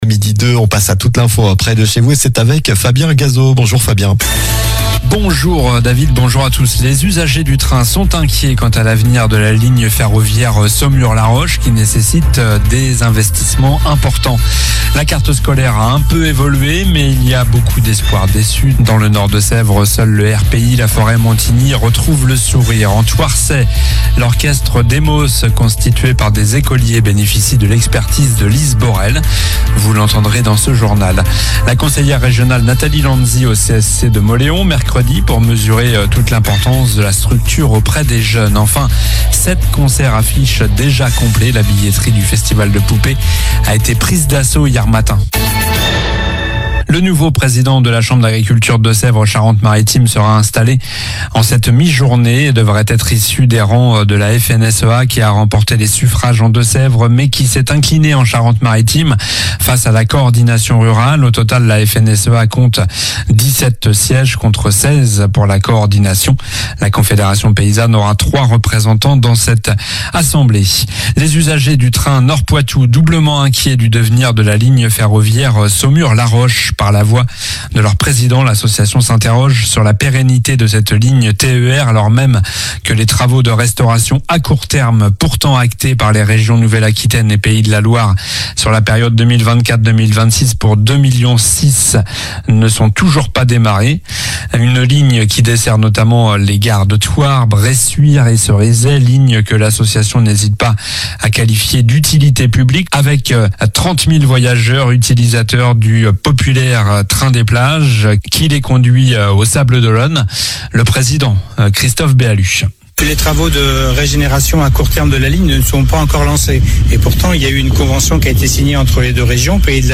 Journal du vendredi 21 février (midi)